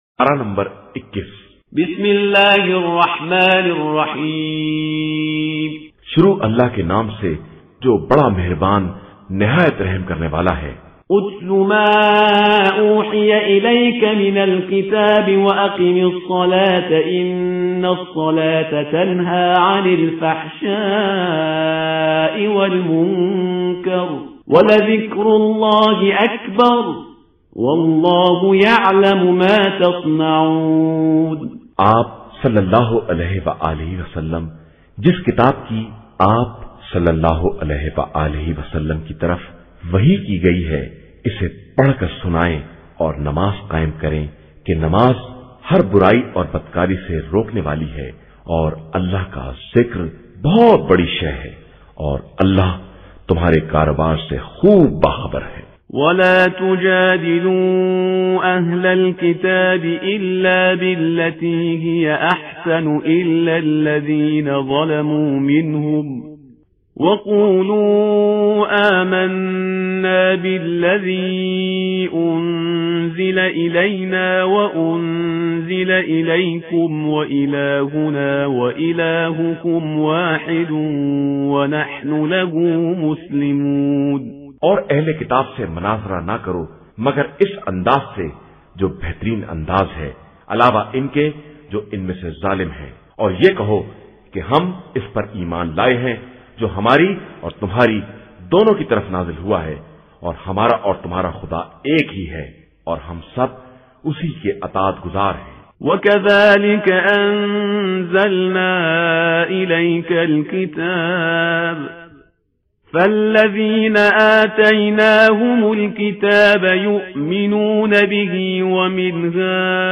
آڈیو | قرآن مجید کی تلاوت، اکیسواں پارہ مع اردو ترجمہ